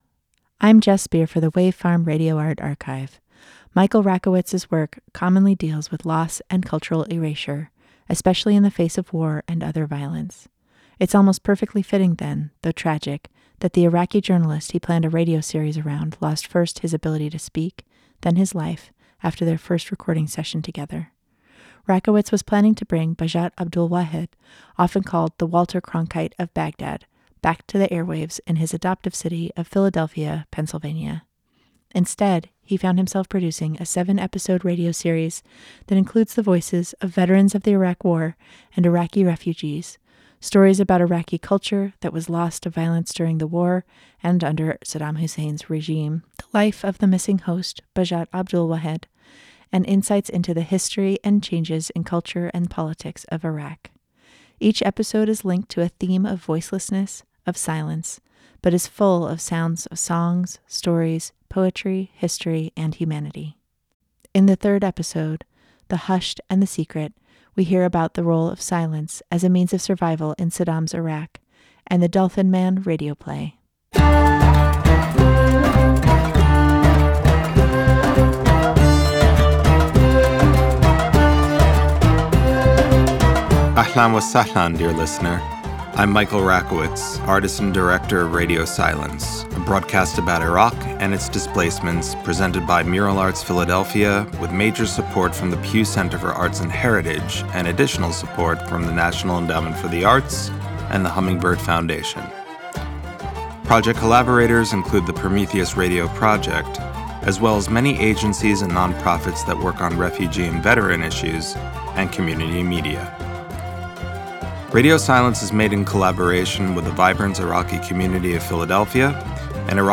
Each episode is linked to a theme of voicelessness, of silence, but is full of sounds of songs, stories, poetry, history, and humanity. As with his other artworks, Rakowitz recruits participants to engage in the art, featuring writing by Iraq war veterans, a radio play by an Iraqi artist, Iraqi music, and interviews with historians, journalists, and everyday people. These sounds weave together different elements of the story of Iraq in the 20th century, sketching out a picture of what was lost, and what is being created both in Iraq and in the diaspora created by decades of colonialism and conflict in Iraq.